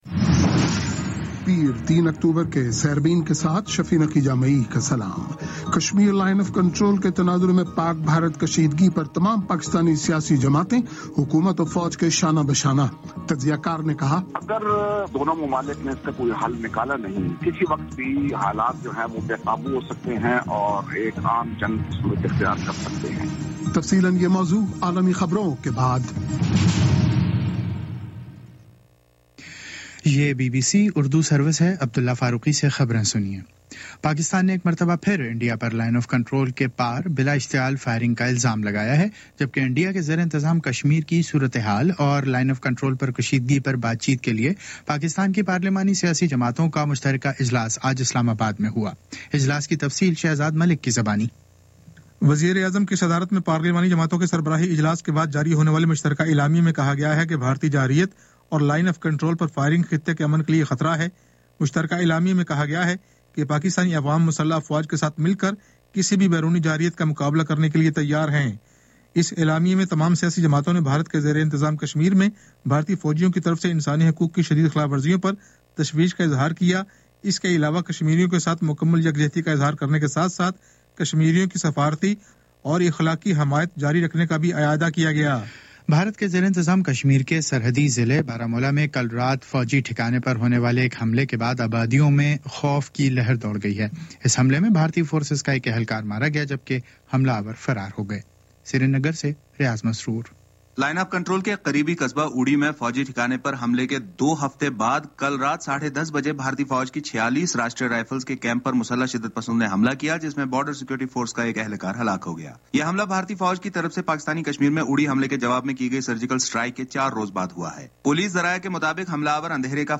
پیر 03 اکتوبر کا سیربین ریڈیو پروگرام